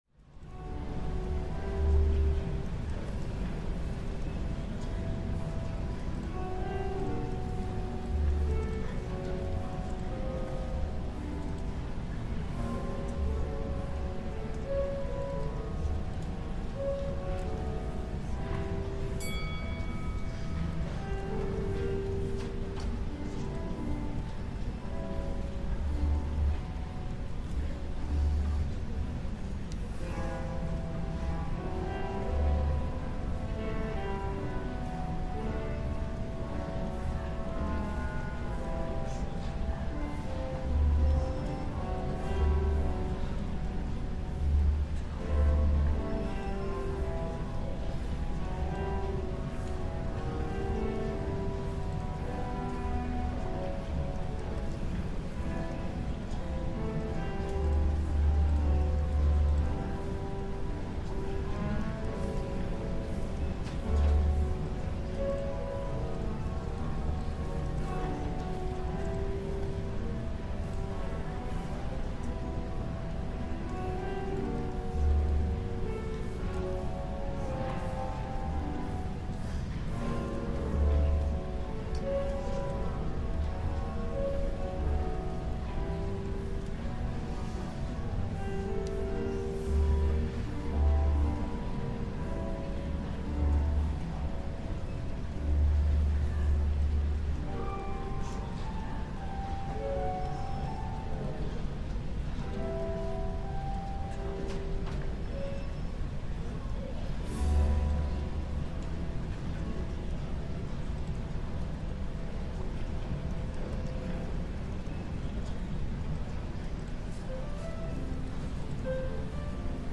Ambiente de cafetería real para concentración durante 1 hora